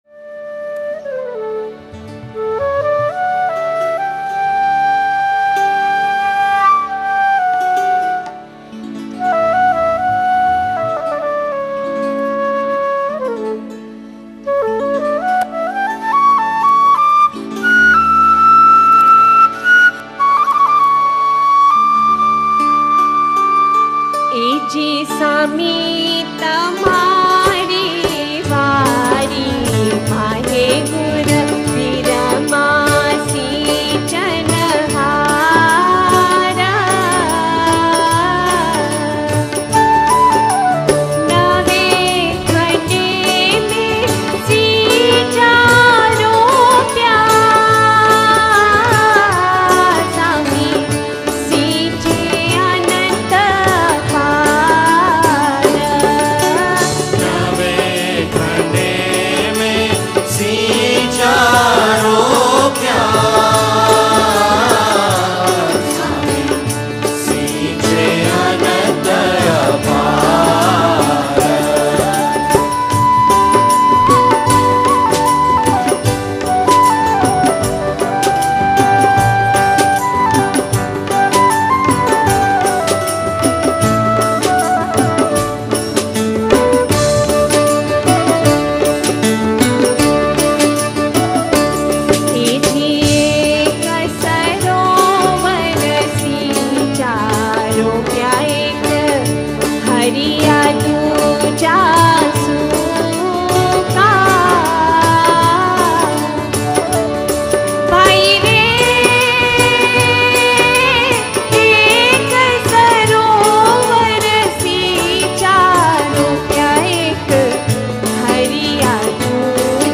Ginan: Saamee tamaaree vaddee maa(n)he – O Lord in your garden
1 With Music: Geet Mehfil Dar es Salaam